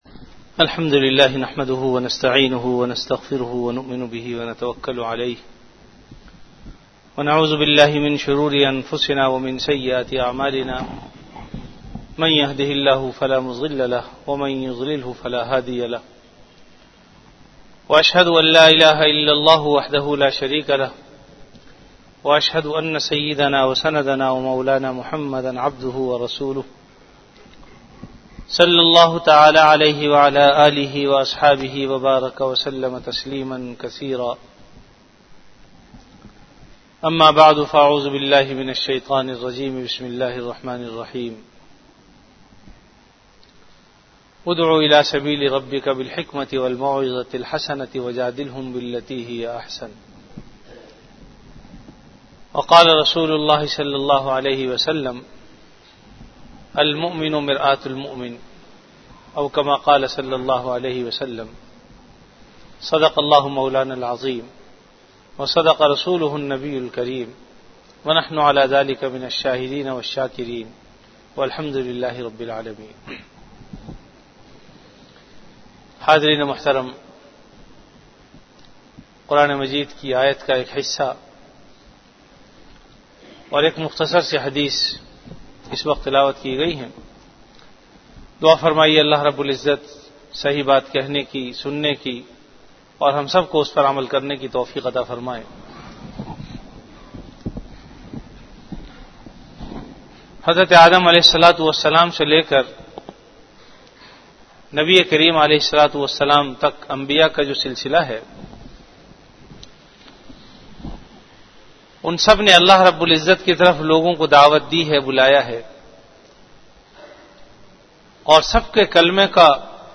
Bayanat · Jamia Masjid Bait-ul-Mukkaram, Karachi
Event / Time Before Juma Prayer